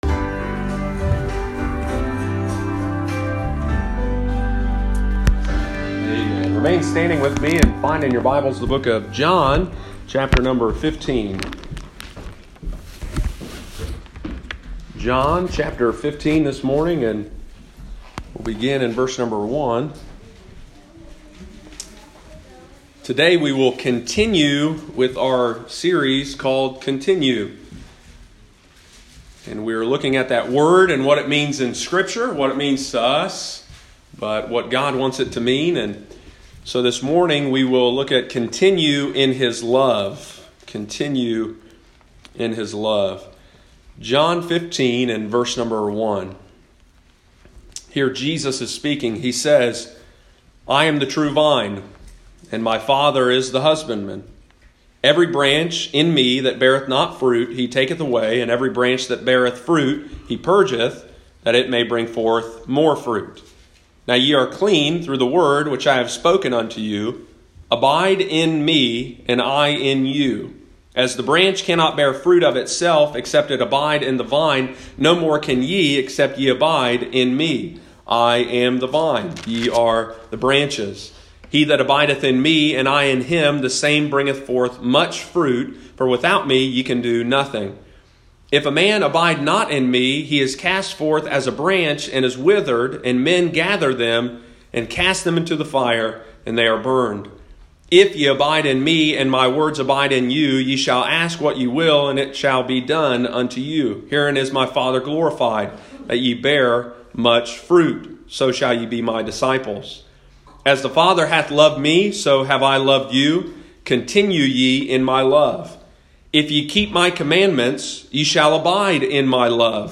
Sunday morning, January 26, 2020.